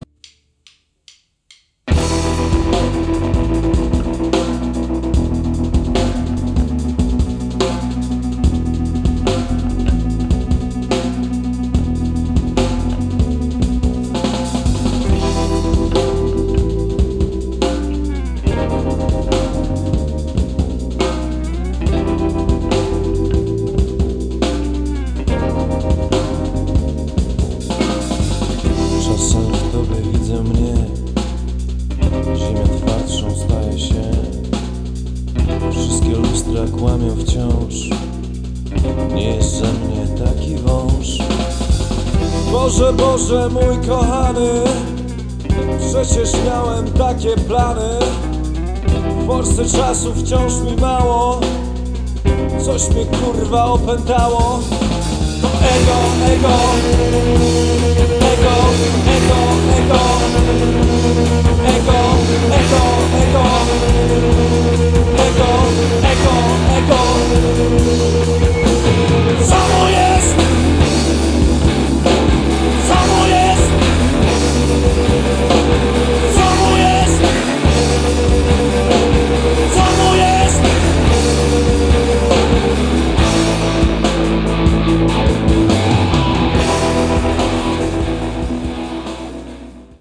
gitarka